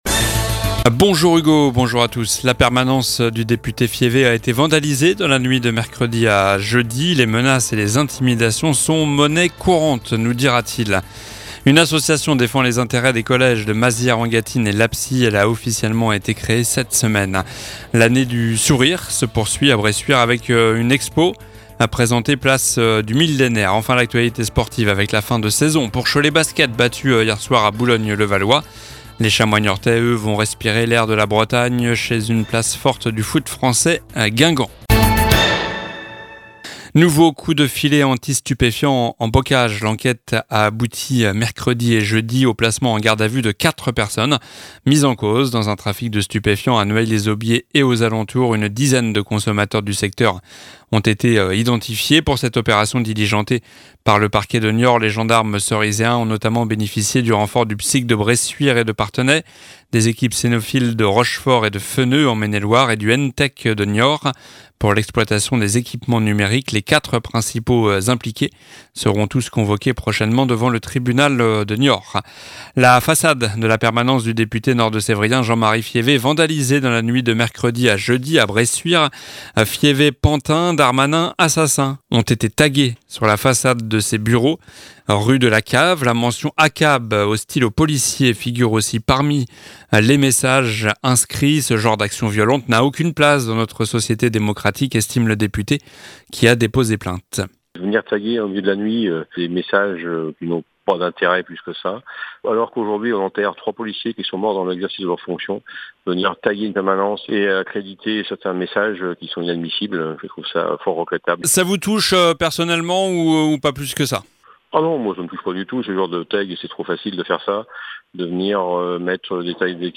Journal du vendredi 26 mai (midi)